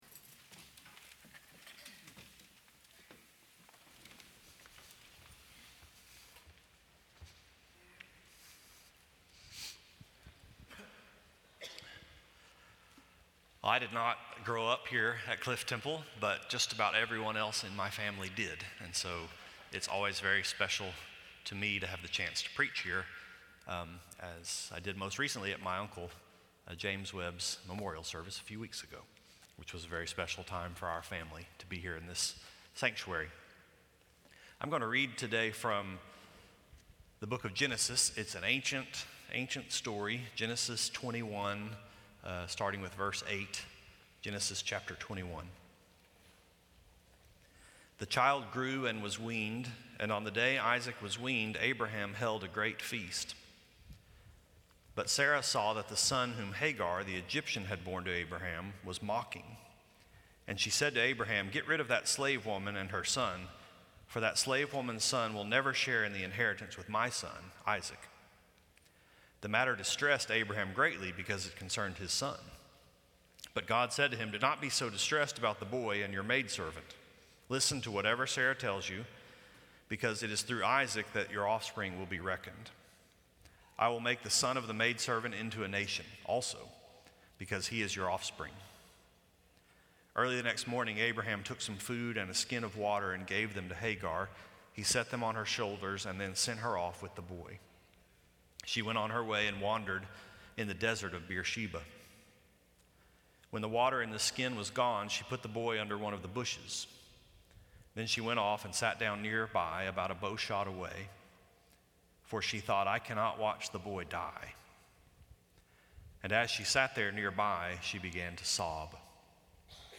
This sermon was preached at Cliff Temple Baptist Church in Dallas, Texas on June 25, 2017 Share this: Share on X (Opens in new window) X Share on Facebook (Opens in new window) Facebook Like Loading...